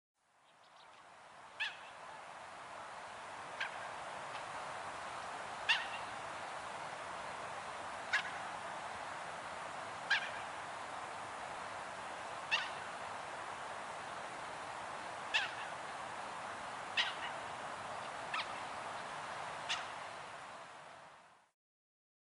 Mandarin Duck
Aix galericulata
Bird Sound
Call is a thin, high, rising "jeeeeee."
MandarinDuck.mp3